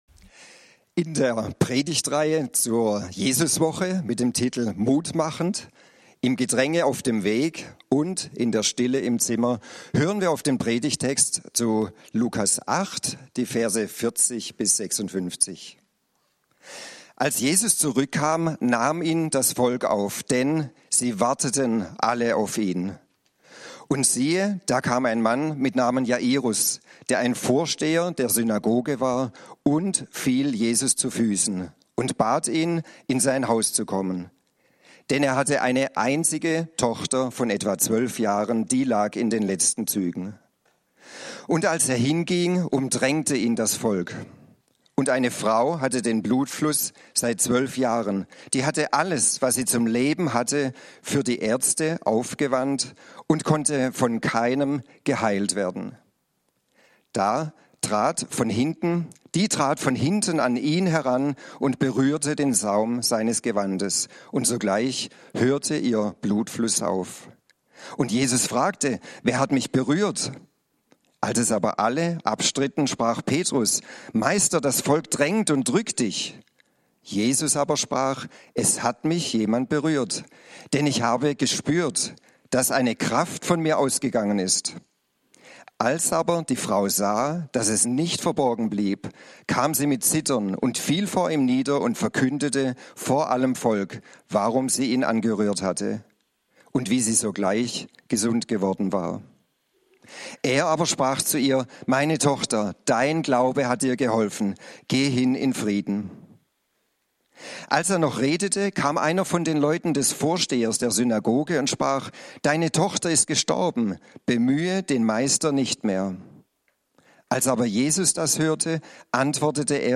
Zum Kalender hinzufügen 22.02.2026 10:00 Jesuswoche 2026 - "Mut machend": Jesusbegegnung im Gedränge auf dem Weg und in der Stille im Zimmer (Lk. 8, 40-56) - Gottesdienst